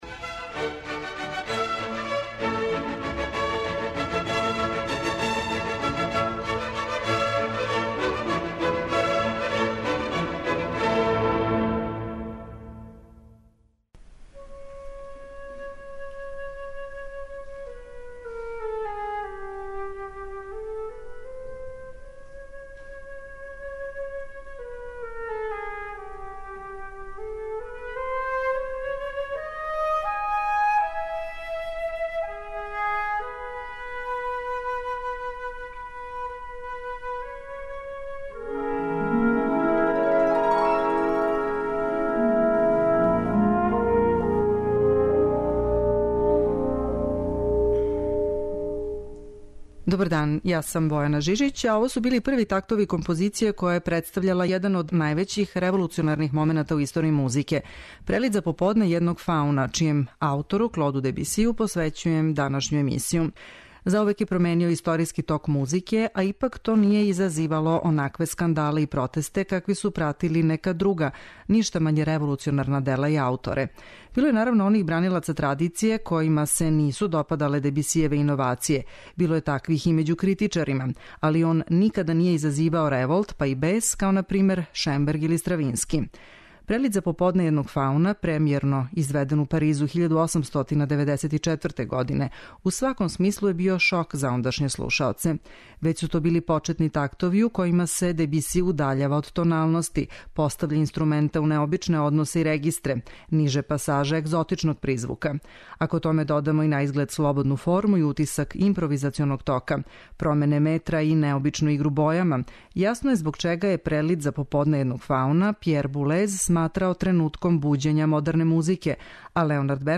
У њој ћемо говорити о Дебисијевој револуционарној улози у музици и слушати неке од његових кључних композиције које су промениле ток музичке историје.